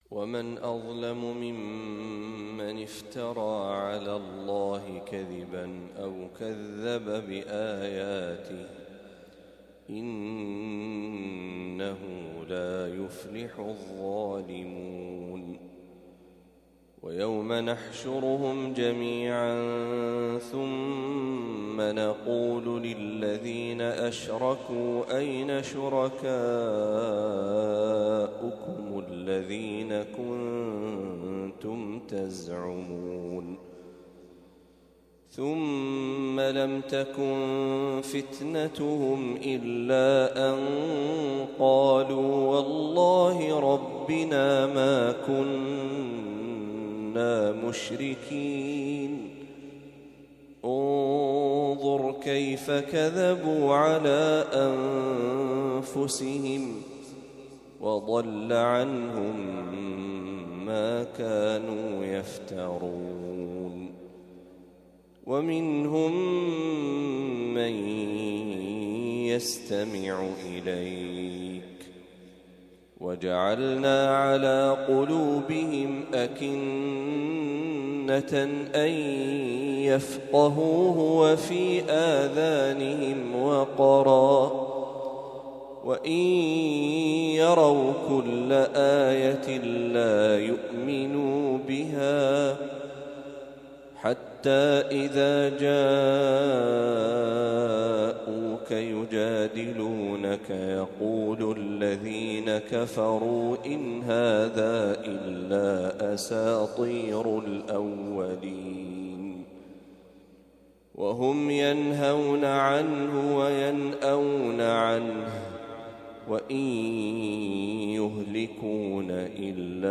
ما تيسر من سورة الأنعام | فجر الإثنين ١٣ ربيع الأول ١٤٤٦هـ > 1446هـ > تلاوات الشيخ محمد برهجي > المزيد - تلاوات الحرمين